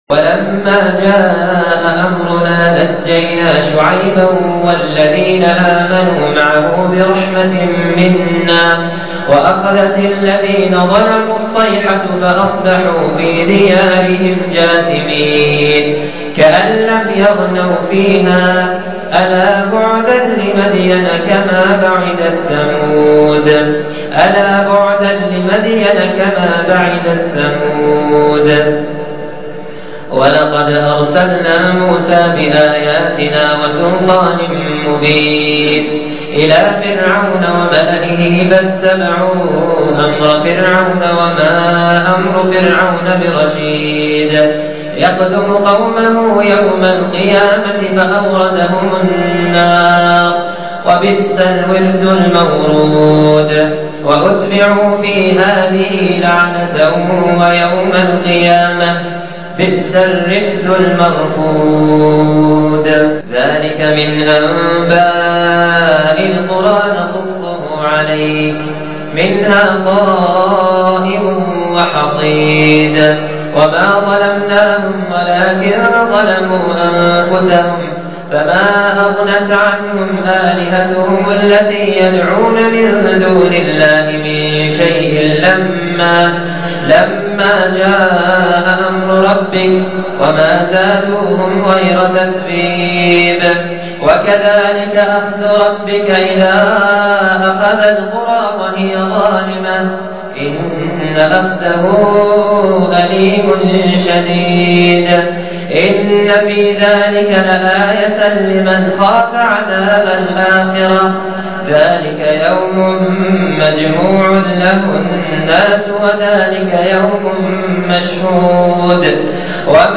من صلاة التراويح لعام 1428هـ
ماشاء الله عليه مجود حافظ وضابط للقرآن وصوته جميل جداً وشجي فأنصحكم الإستماع إليه من خلال هذا الرابط المباشر